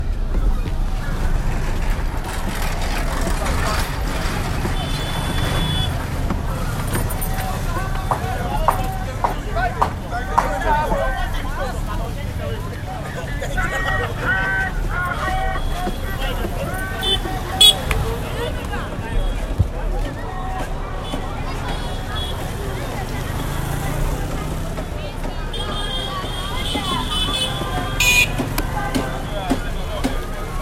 Not to mention the continuous horn honking of the okadas.
The noise along the main roads are even more noisy that the community.
road-2.m4a